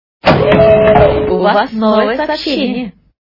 » Звуки » звуки для СМС » Звук для СМС - У Вас новое сообщение
При прослушивании Звук для СМС - У Вас новое сообщение качество понижено и присутствуют гудки.